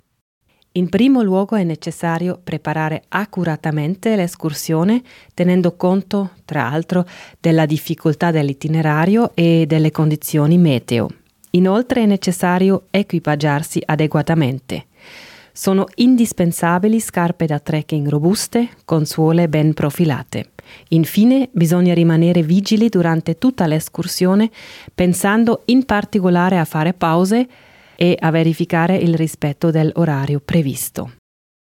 portavoce